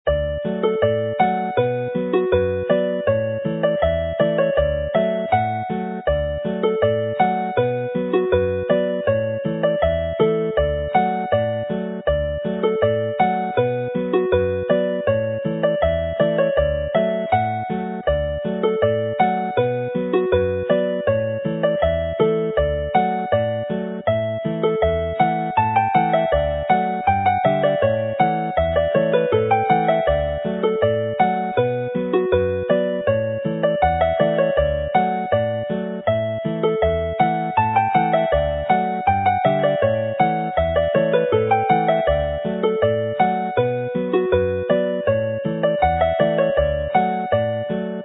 Uchder Cader Idris (in D)
The Height of Cader Idris runs well as a dance tune; its meoldy contrasts with Agoriad y Blodau but relates directly to the third tune in the set.